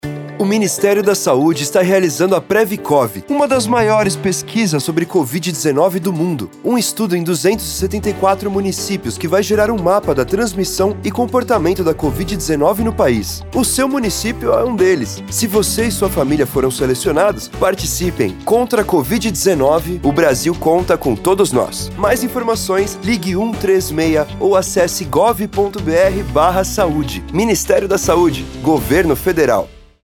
Spot - PrevCOV 5.5 .mp3 — Ministério da Saúde